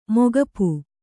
♪ mogapu